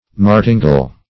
Martingale \Mar"tin*gale\, Martingal \Mar"tin*gal\, n. [F.